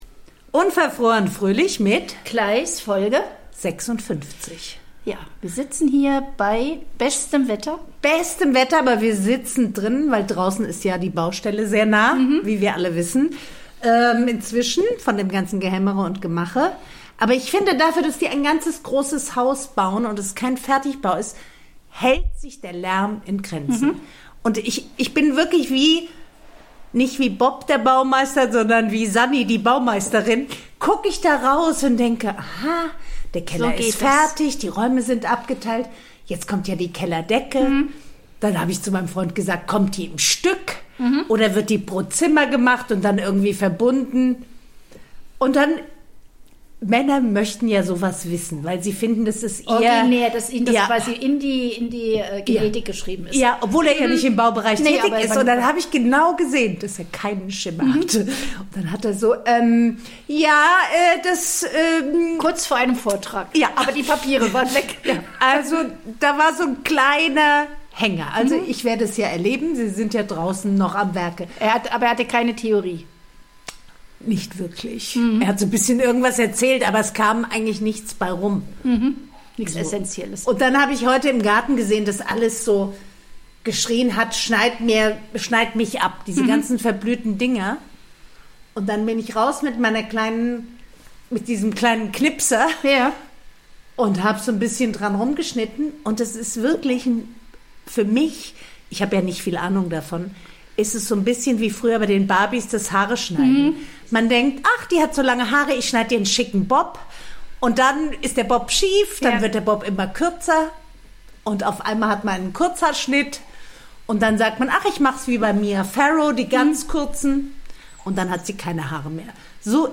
reden die beiden Podcasterinnen über Herzenssachen, himmlisches Wetter und herrliche Lektüre.